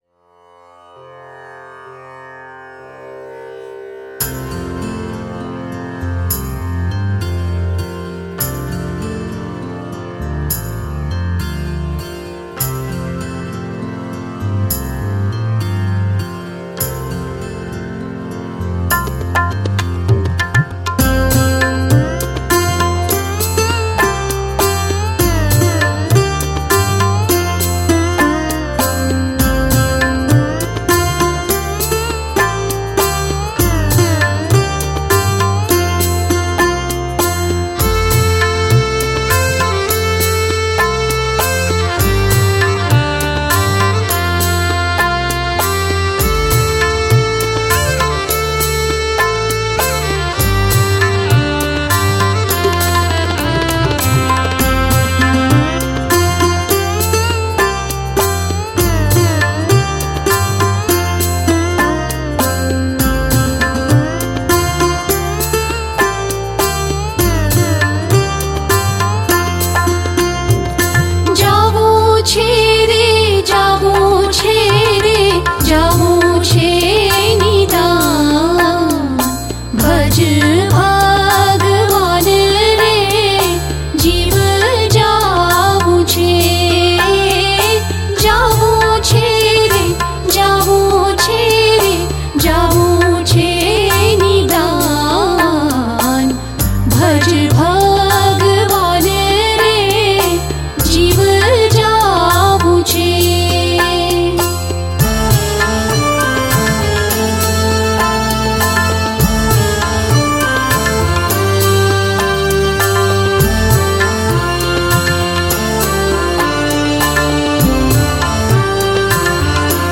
🎵 Jāvuñ Chhe Re / જાવું છે રે – રાગ : ગરબી – પદ – ૧